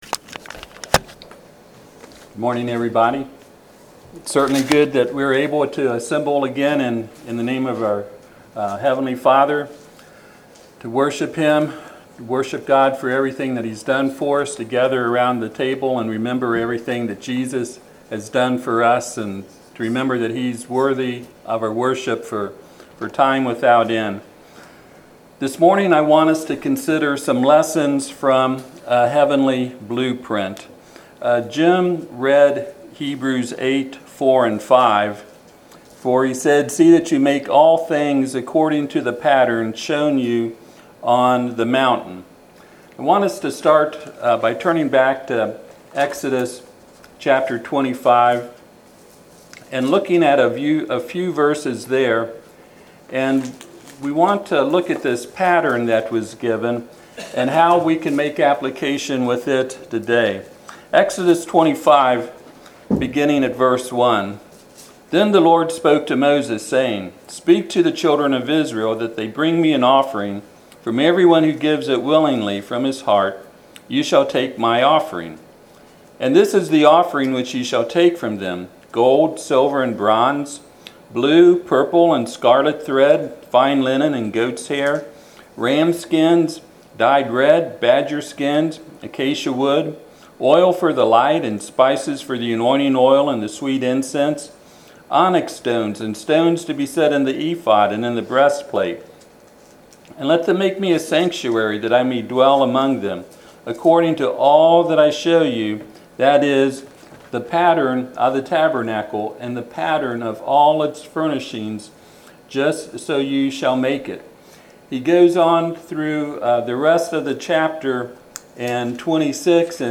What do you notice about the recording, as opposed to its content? Passage: Hebrews 8:4-5 Service Type: Sunday AM « Five Wonderful Things From John 5 Some of God’s people in the wrong place with the wrong attitude.